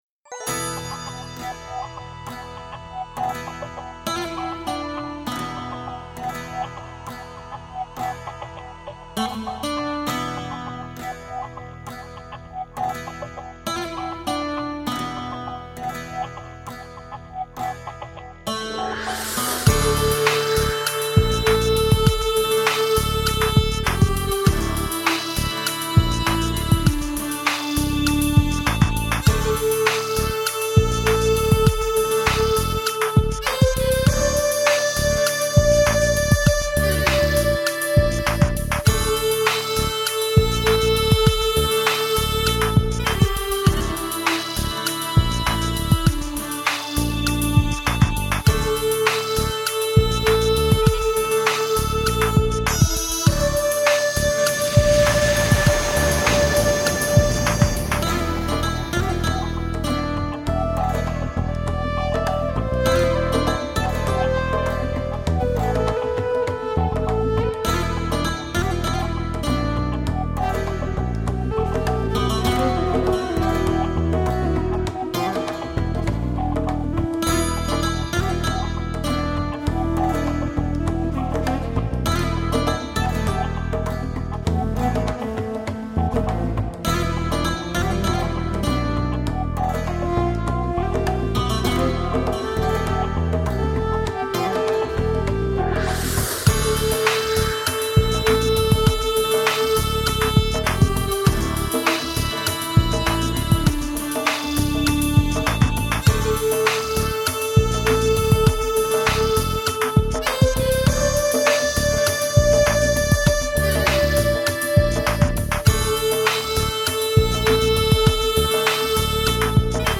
巴尔干半岛风情